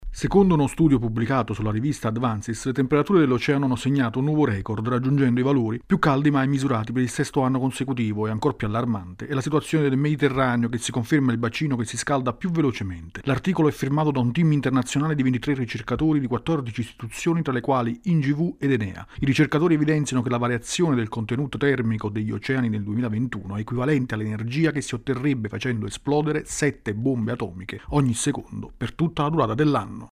Allarme rosso per gli oceani e per il Mediterraneo con il surriscaldamento rilevato da uno studio internazionale. Il servizio